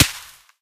dc0f4c9042 Divergent / mods / JSRS Sound Mod / gamedata / sounds / material / bullet / collide / sand02gr.ogg 18 KiB (Stored with Git LFS) Raw History
sand02gr.ogg